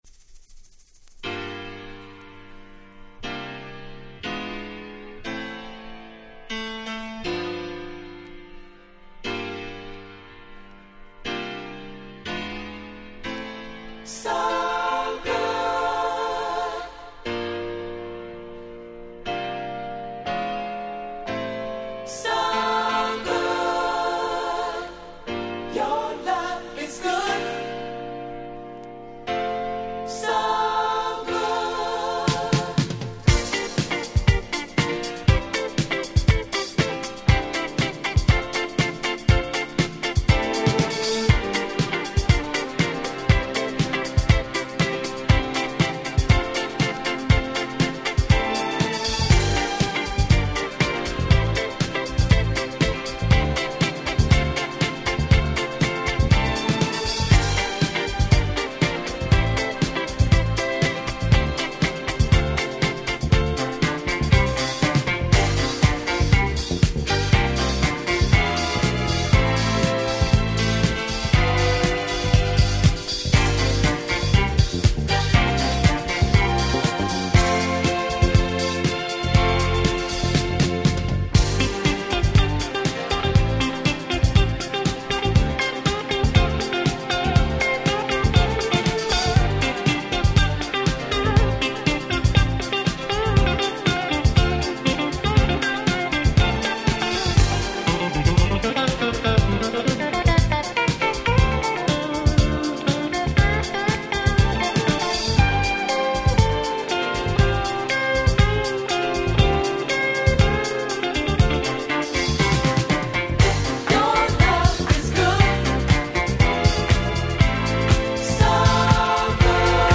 Nice Re-Edit !!